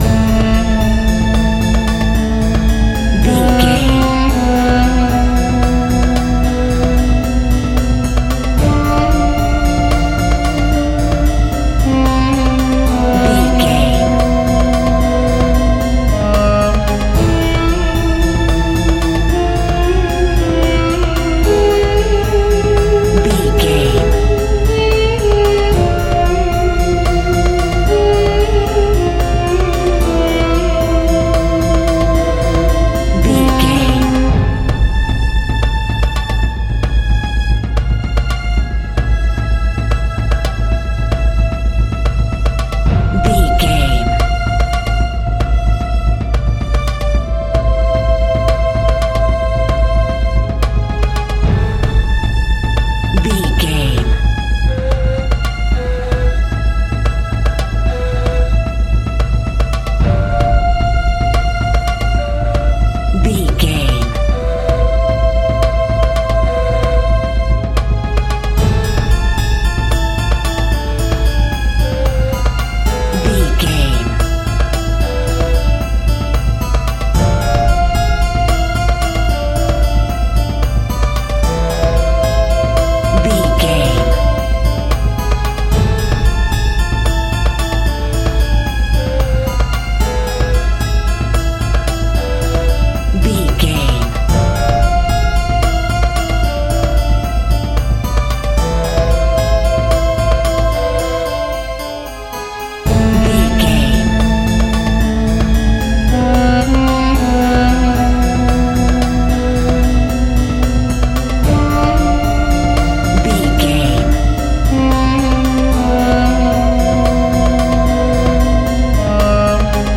Aeolian/Minor
ethnic percussion